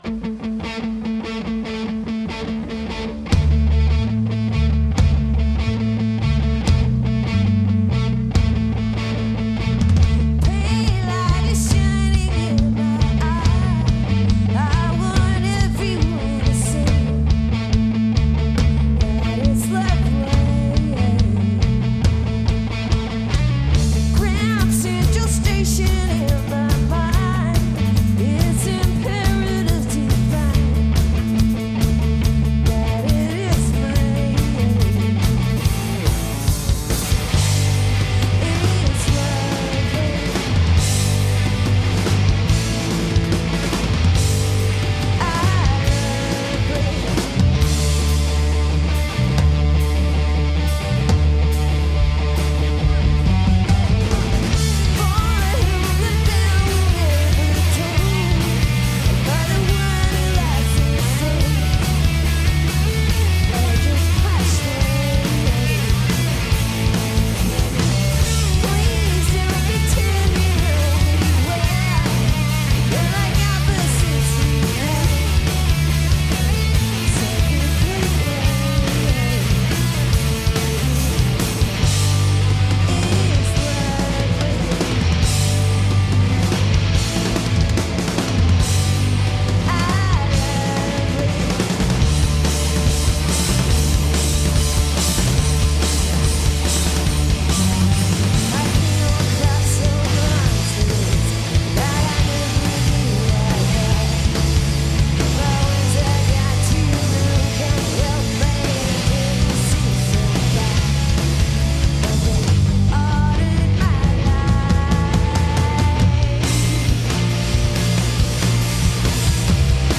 Indie bands